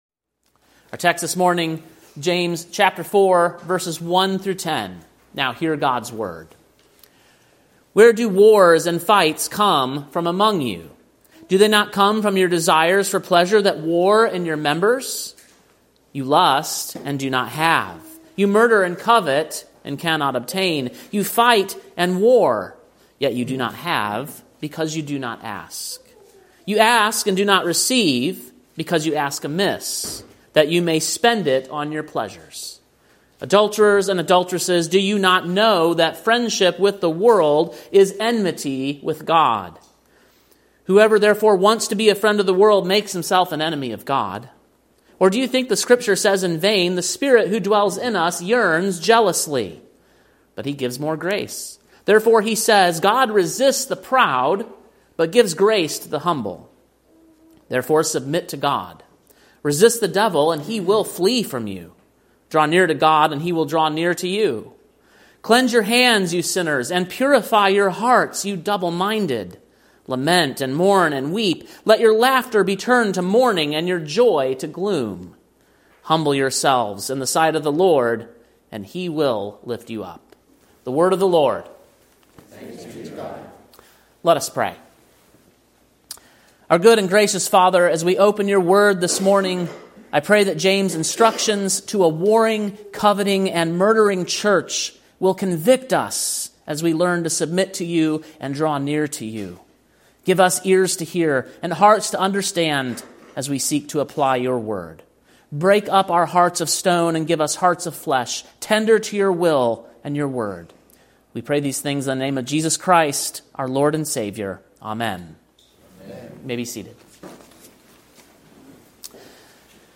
Sermon preached on January 26, 2025, at King’s Cross Reformed, Columbia, TN.